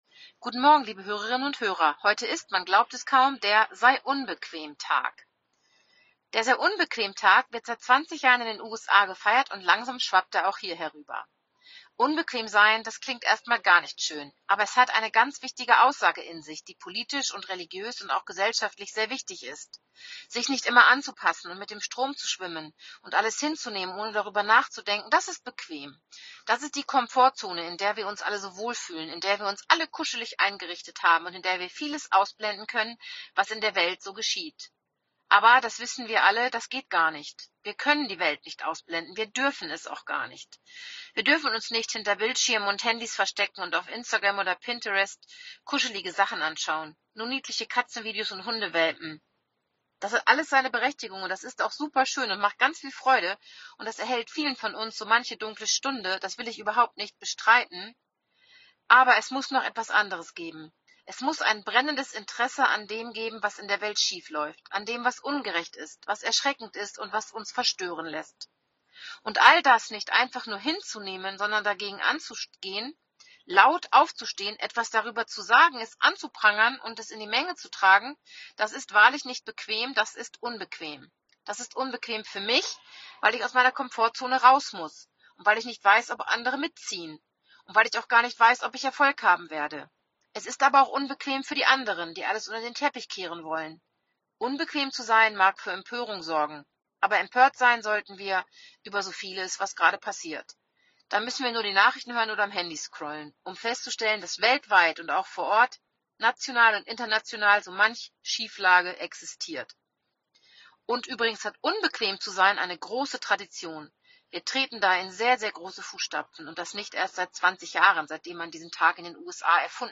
Radioandacht vom 25. Februar